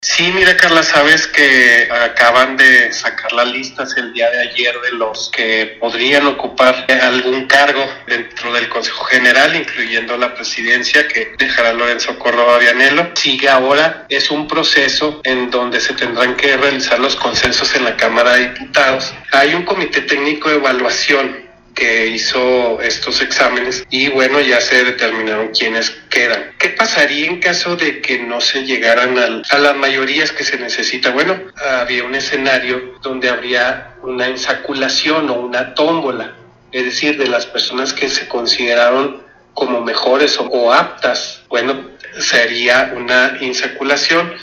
El magistrado Hugo Molina, explicó que, si los diputados llegan al escenario de no acordar qué personas integrarían el Consejo General del INE, seguiría el proceso de insaculación.